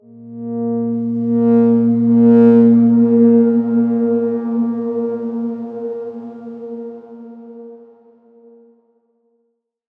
X_Darkswarm-A#2-mf.wav